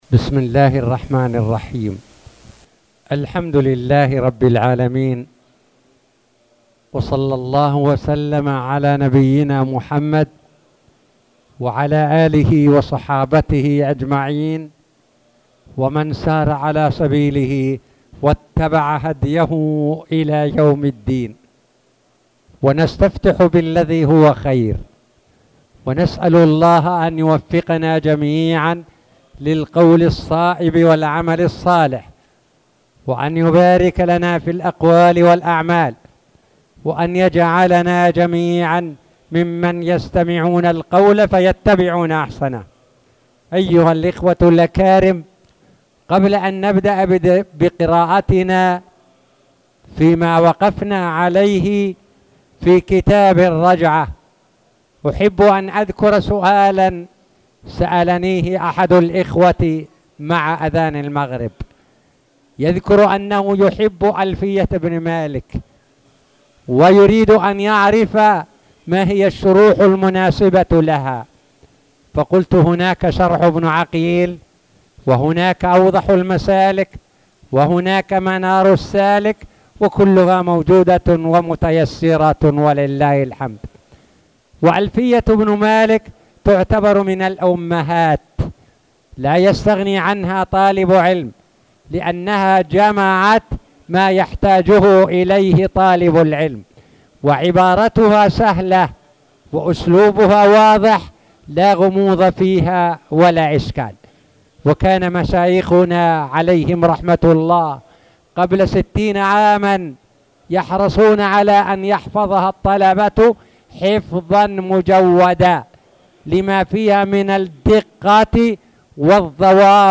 تاريخ النشر ٤ شعبان ١٤٣٨ هـ المكان: المسجد الحرام الشيخ